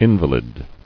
[in·va·lid]